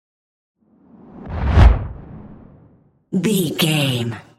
Dramatic whoosh deep trailer
Sound Effects
Atonal
dark
intense
tension
whoosh